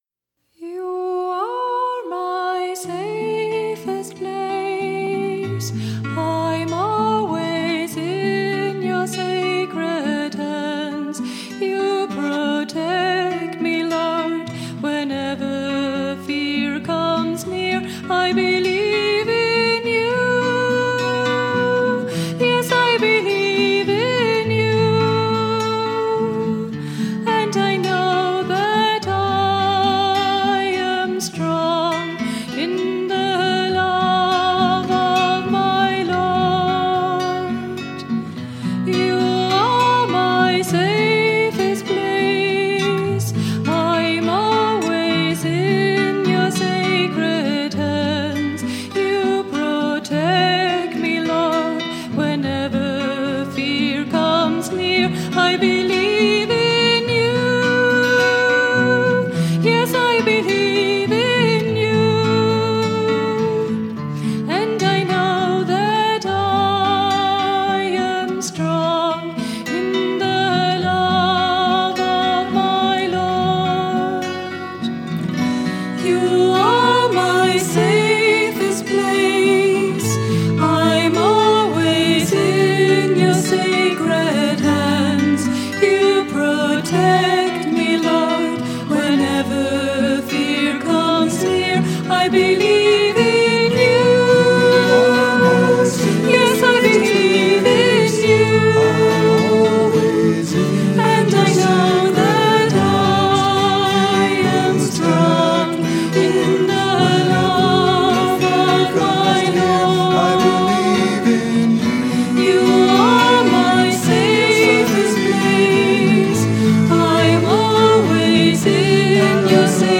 1. Devotional Songs
Minor (~ Natabhairavi)
8 Beat / Keherwa / Adi
Slow
Lowest Note: R2 / D
Highest Note: S / C (higher octave)